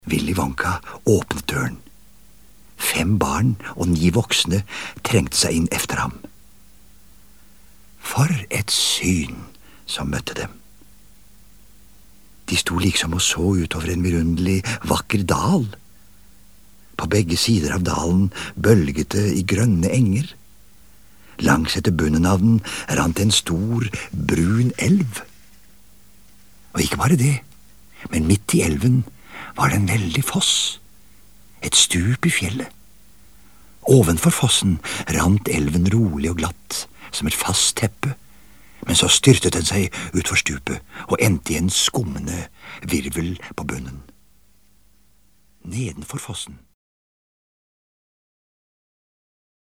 Opplesinga av denne boka er blitt sendt i NRK og gitt ut på cd.
Det er Frank Robert som les opp Roald Dahls tekst.
Den einaste påverknaden det lydlege kan ha i dette tilfellet, er den stemninga av noko fantastisk og vanvittig, som Frank Robert får fram gjennom måten han les det på.